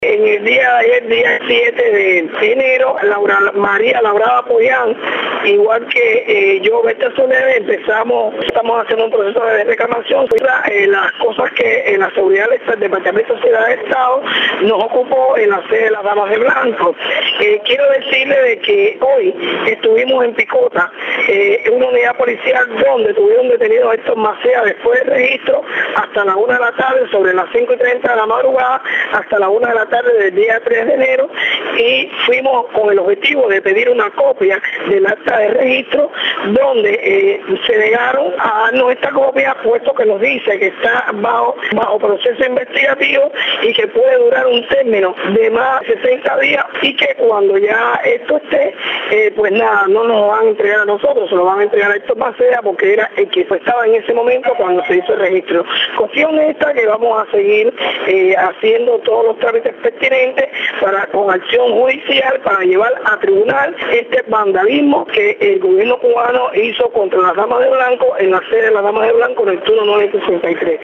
Palabras de Berta Soler en entrevista con Radio Martí